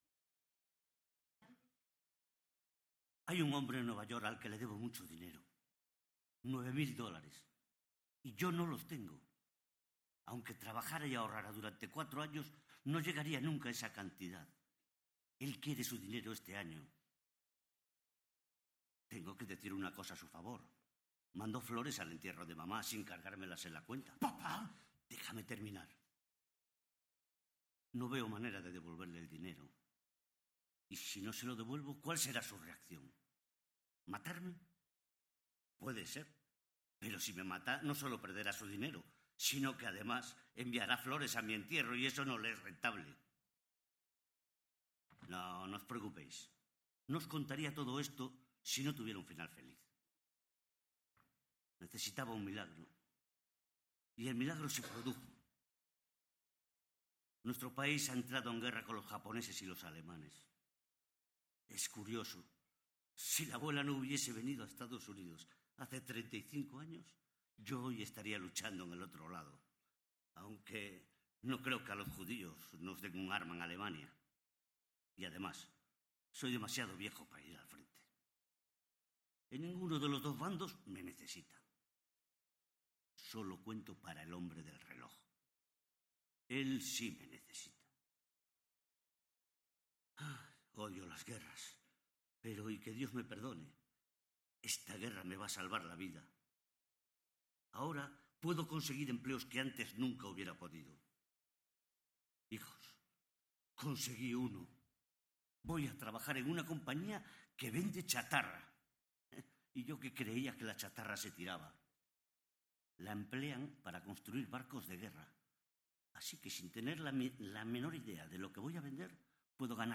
“Perdidos en Yonkers” formato MP3 audio(3,99 MB), de Neil Simon. “Teantro ha hecho una buena versión de la obra, con un impecable trabajo que exhibe un óptimo ritmo y una muy buena interiorización de personajes con la que logran las notables interpretaciones, trabajadas con un rigor muy destacable,